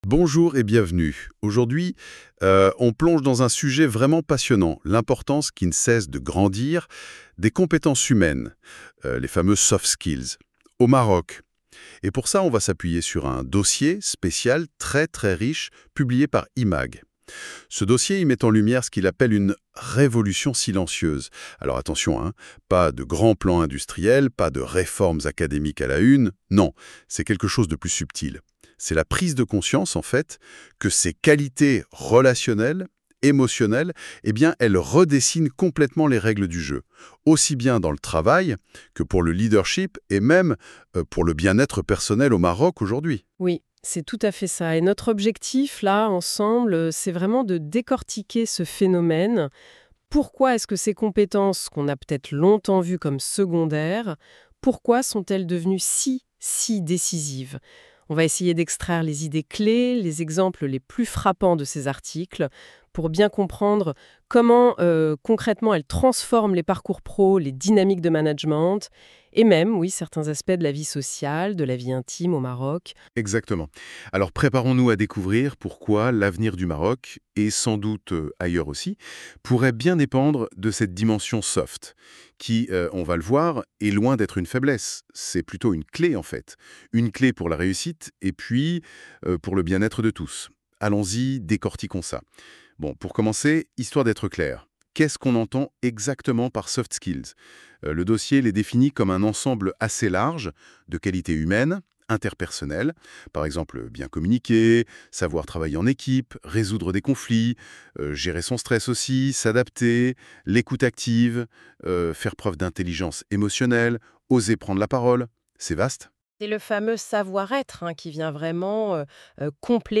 Débat (10.87 Mo) Qu'est-ce que les "soft skills" et pourquoi sont-elles devenues si importantes au Maroc ? Comment les soft skills transforment-elles le leadership managérial au Maroc ?